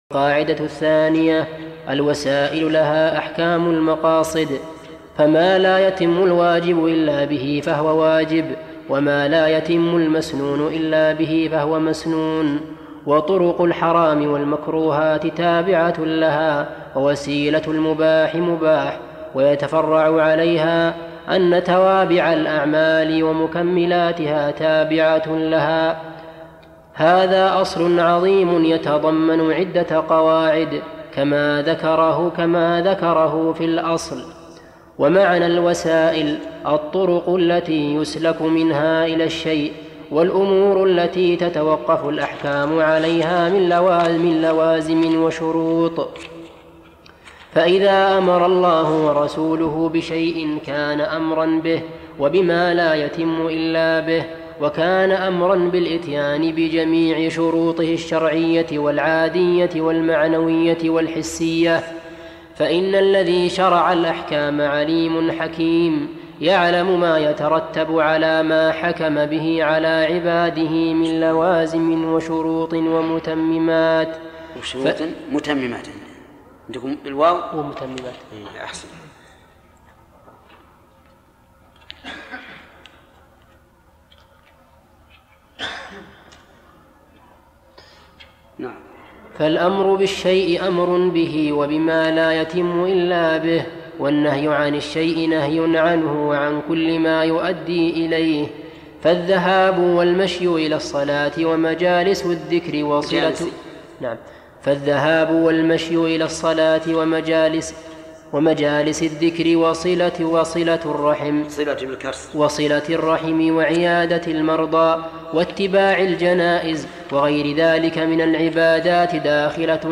ارسل فتوى عبر "الواتساب" ينبوع الصوتيات الشيخ محمد بن صالح العثيمين فوائد من التعليق على القواعد والأصول الجامعة - شرح الشيخ محمد بن صالح العثيمين المادة 5 - 148 القاعدة الثانية الوسائل لها أحكام المقاصد فما لا يتم...